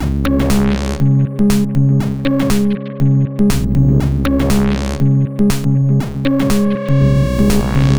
/samples/CHIPSHOP_DELUXE/CHIPSHOP_LOOPS/120_BPM/
ChipShop_120_Combo_C#_02.wav